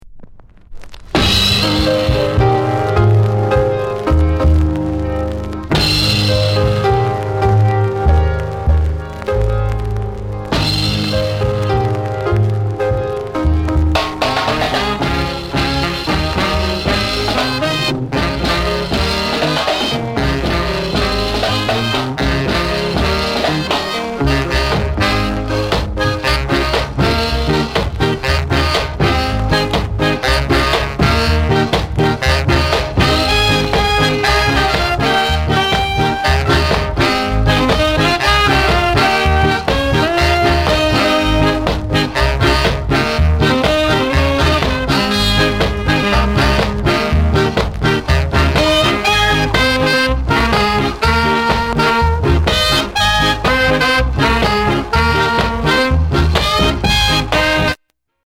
NICE SKA INST